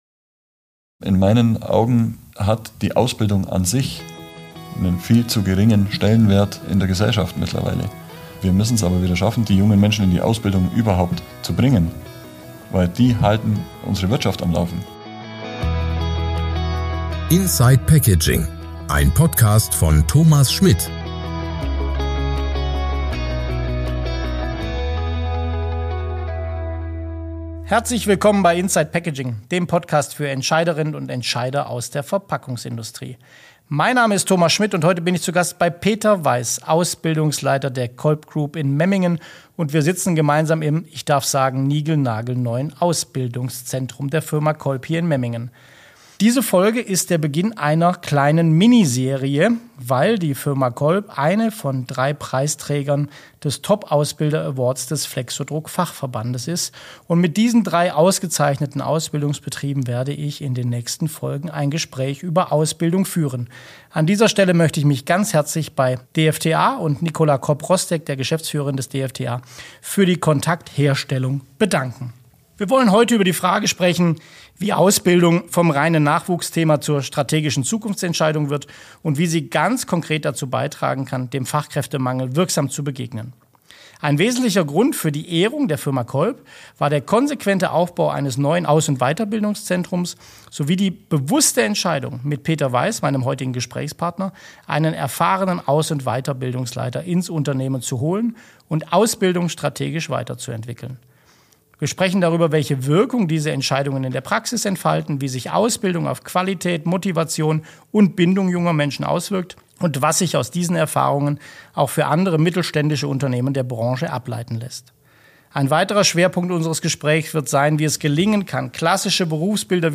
Gemeinsam diskutieren wir, wie klassische Berufe wie der Packmitteltechnologe attraktiver werden und was Unternehmen, Verbände und Ausbilder dafür tun können. Begleiten sie mich auf der Tonspur ins neue Aus- und Weiterbildungszentrum der Firma Kolb und hören sie, wie Kolb mit kreativen Ansätzen und persönlicher Ansprache dem Fachkräftemangel begegnet.